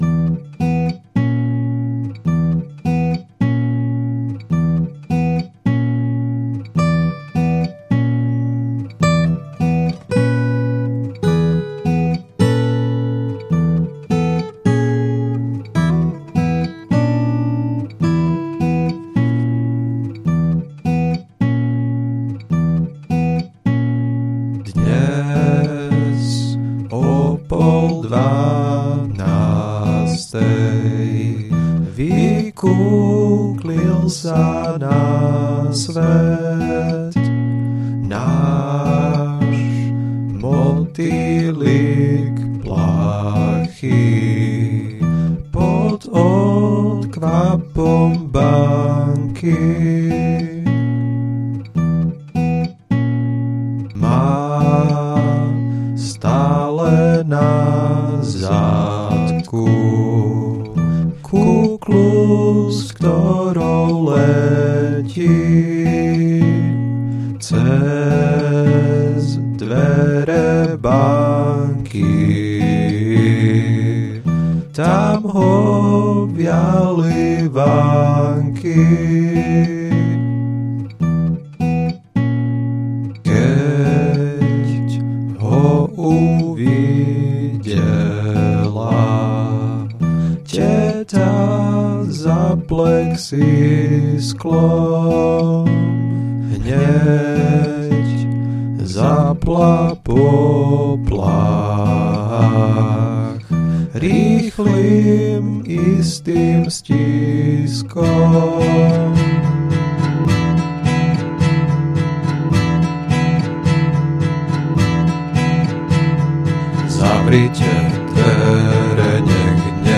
kategorie ostatní/písně
To máš dvojhlasne?